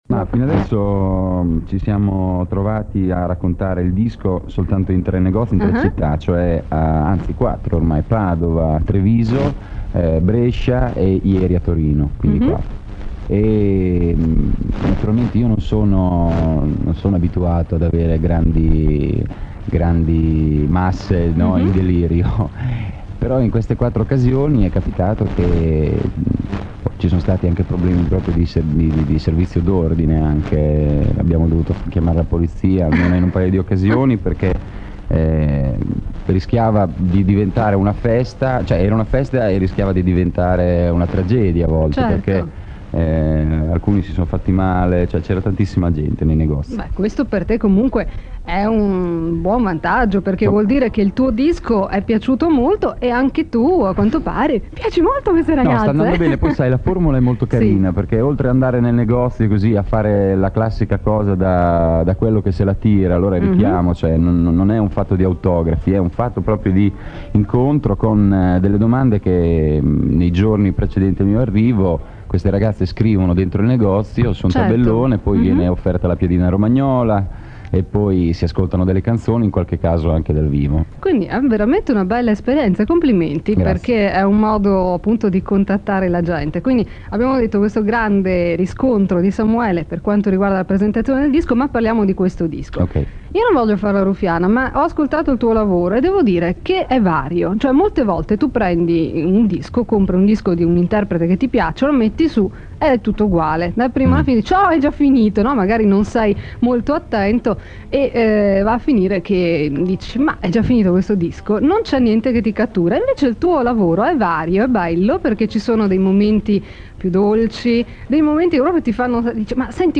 Un ampio estratto dell’intervista, quasi completamente centrata sull’album “Freak”. Il metodo di scrittura di Bersani, l’amore per il cinema, i testi spiegati brano per brano… Si comincia raccontando l’incontenibile entusiasmo dei fans all’uscita del disco.